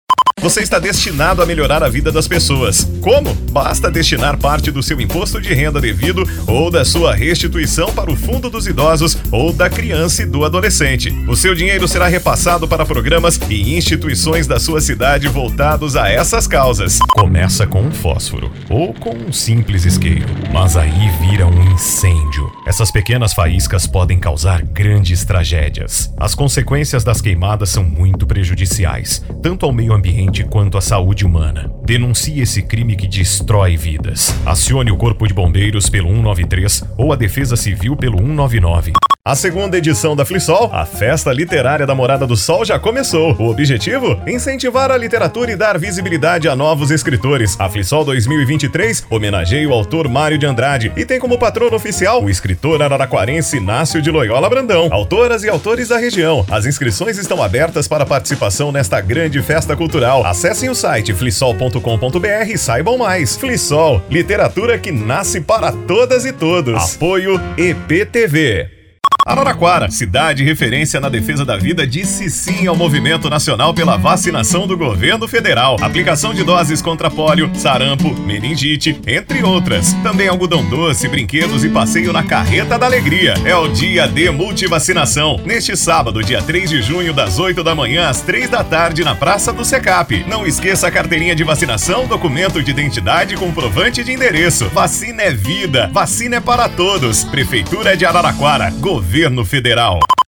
Spot Comercial
Vinhetas
Padrão
Impacto
Animada